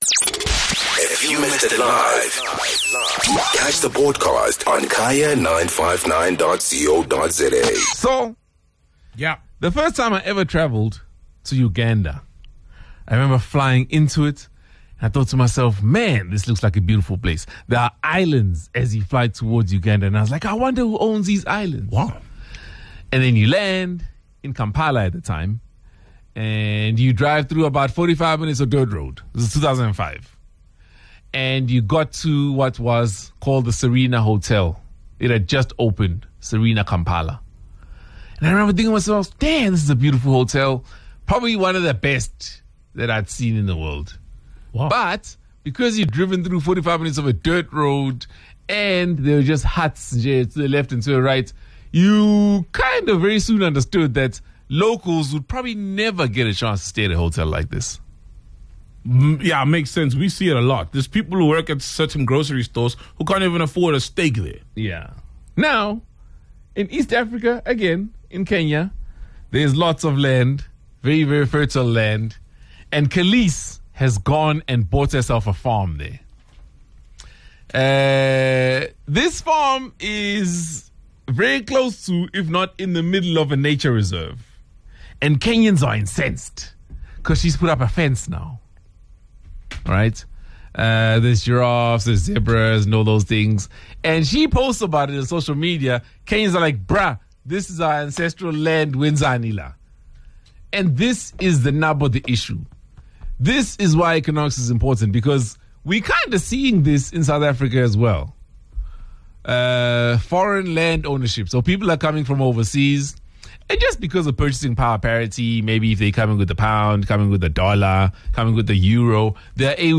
Listeners also shared their views about foreigners flocking into South Africa to buy land or property.